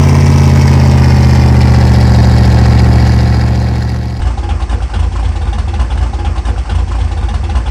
Index of /server/sound/vehicles/lwcars/quadbike
slowdown.wav